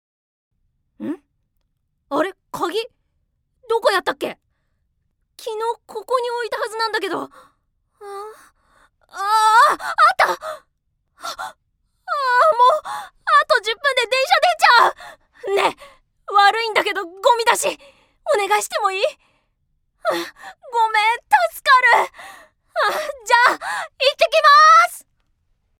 ◆ナチュラル◆